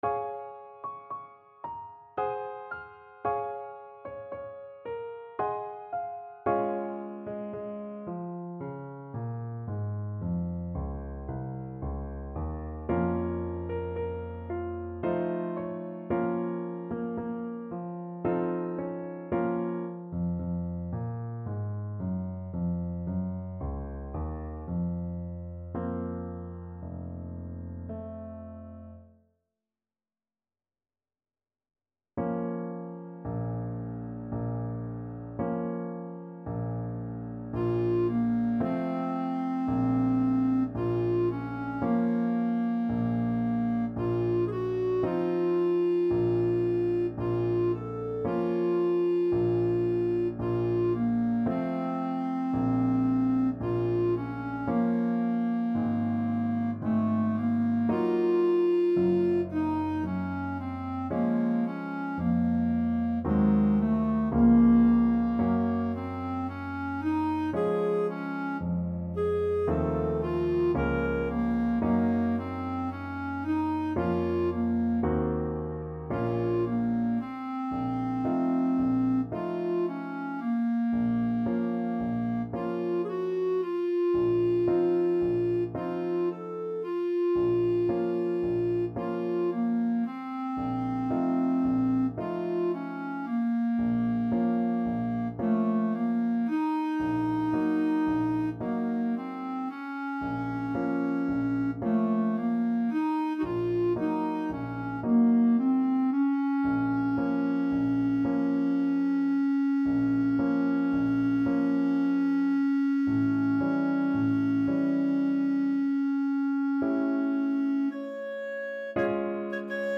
Clarinet
Db major (Sounding Pitch) Eb major (Clarinet in Bb) (View more Db major Music for Clarinet )
~ = 56 Andante
3/4 (View more 3/4 Music)
Classical (View more Classical Clarinet Music)